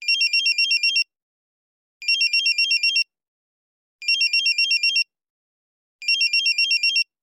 Звук у врача пищит бипер